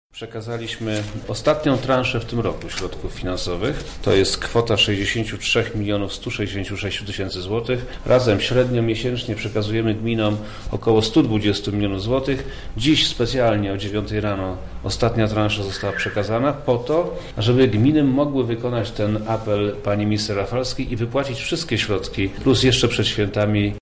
O szczegółach mówi Przemysław Czarnek – Wojewoda Lubelski